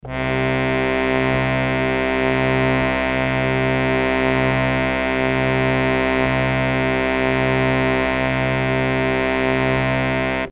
harmonium
C2.mp3